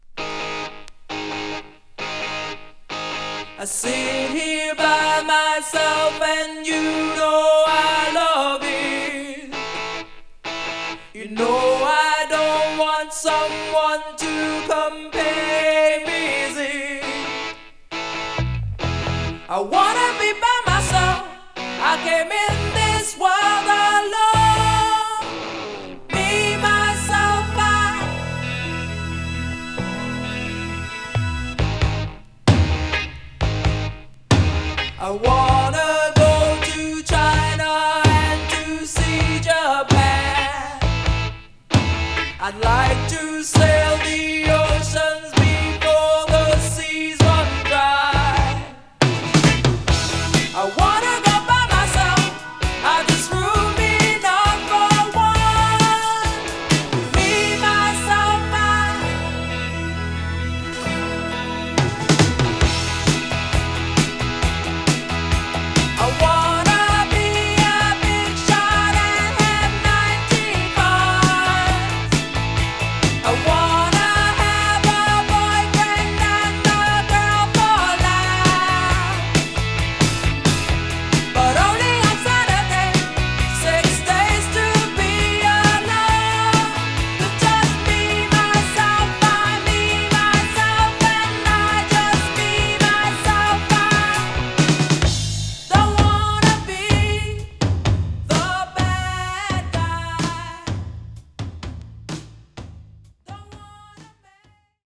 実力派ブリティッシュS.S.W.